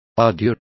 Complete with pronunciation of the translation of adieu.